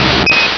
pokeemerald / sound / direct_sound_samples / cries / kabuto.aif
kabuto.aif